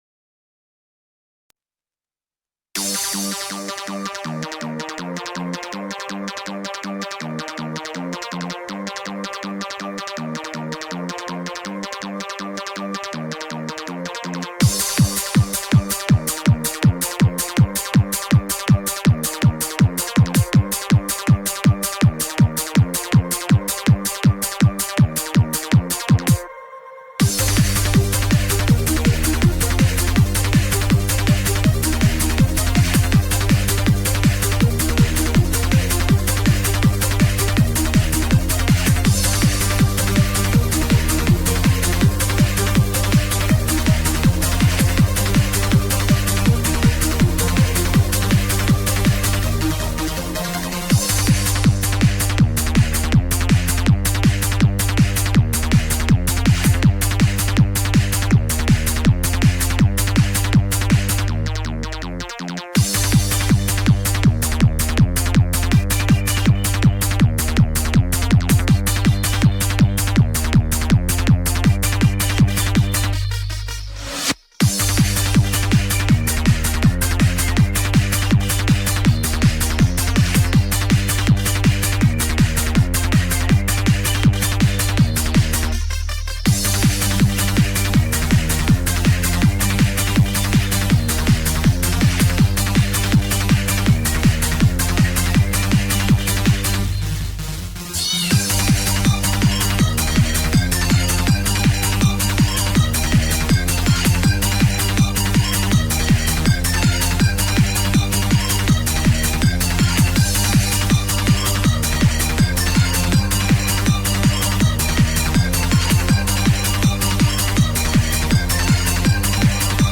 __EURODANCE__.mp3